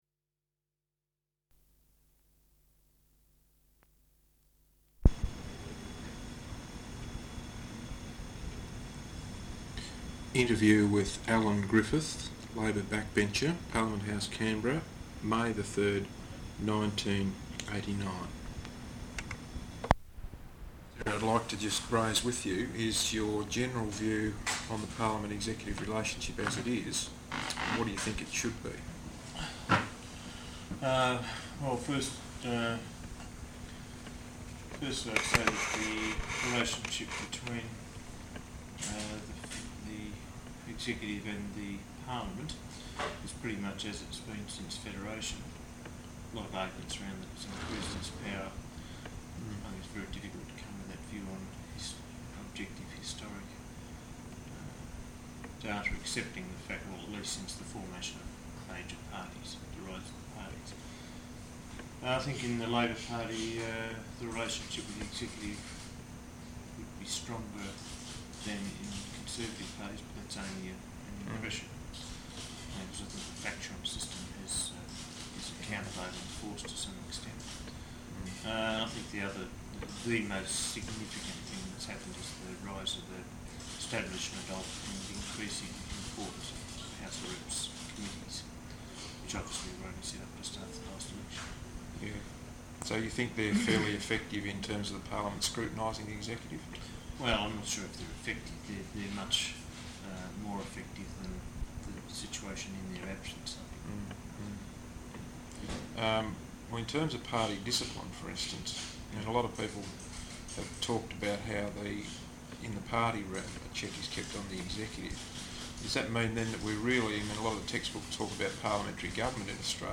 Interview with Alan Griffiths, Labor Backbencher, Parliament House, Canberra May 3rd 1989.